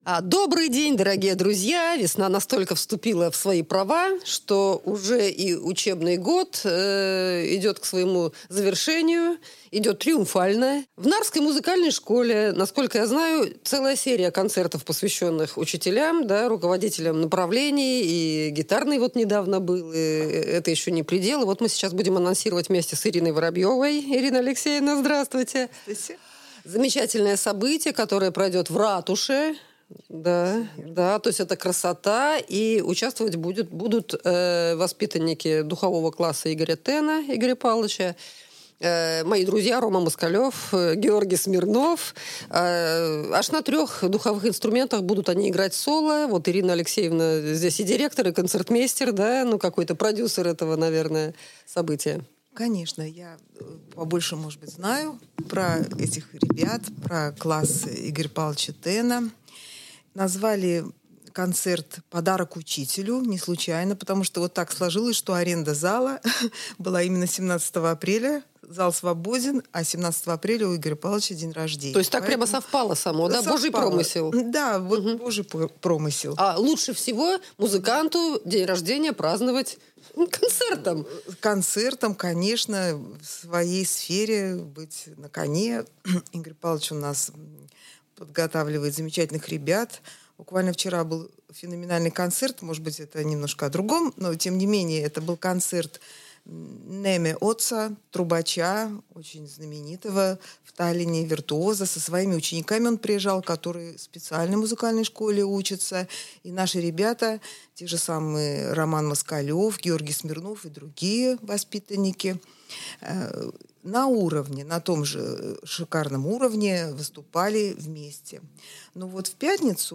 гость нашей студии.